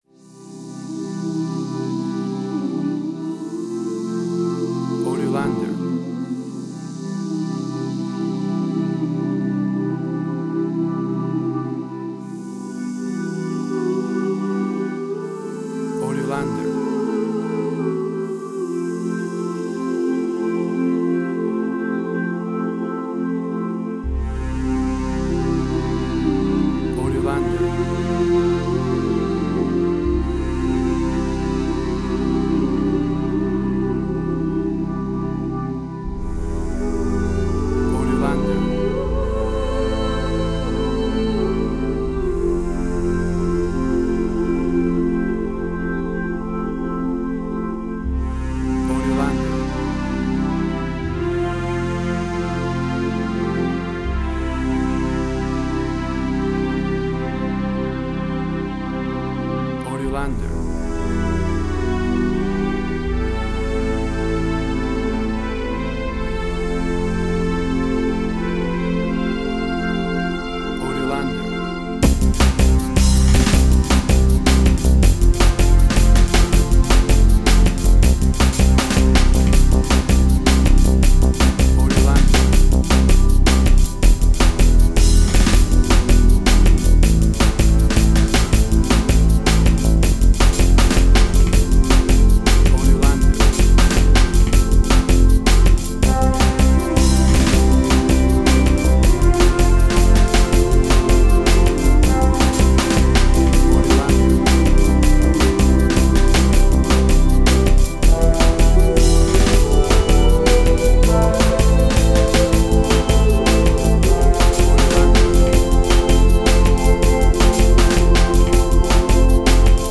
emotional music
Tempo (BPM): 160